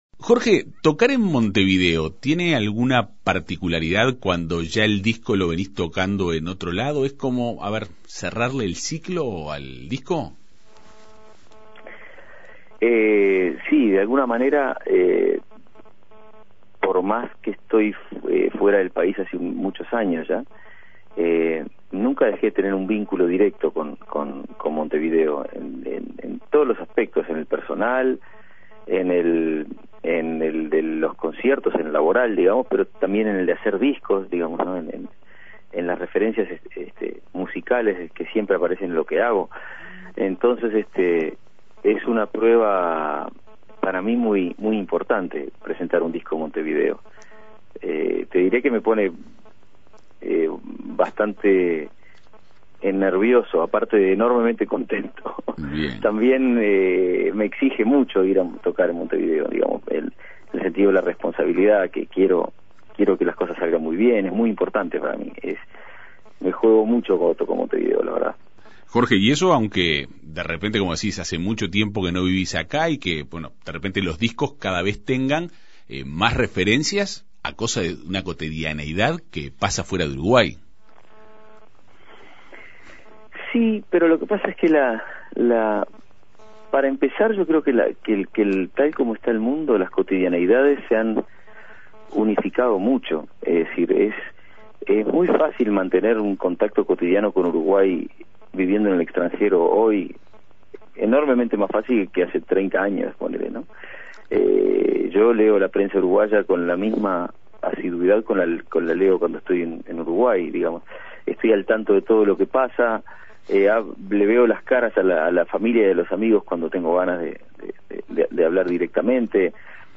El artista dialogó en la Segunda Mañana de En Perspectiva.